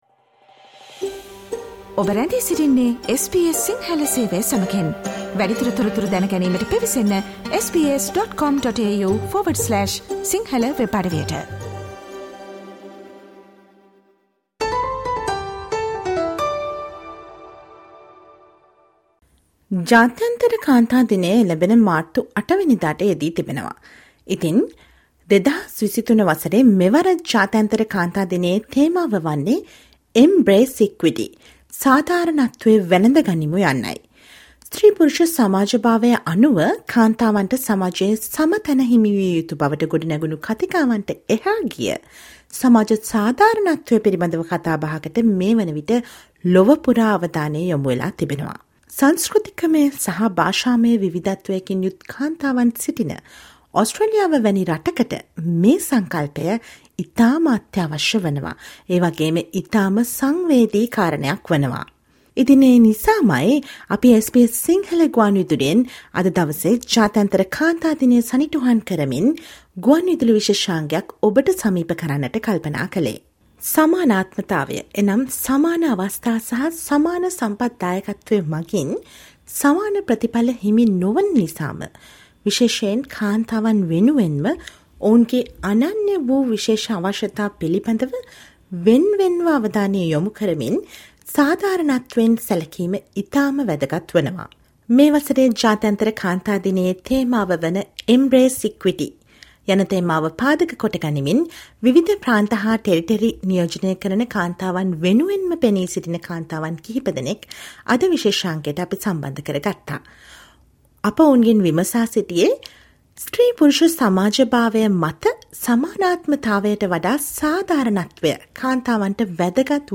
Listen to the SBS Sinhala radio feature to mark the international women's day in 2023.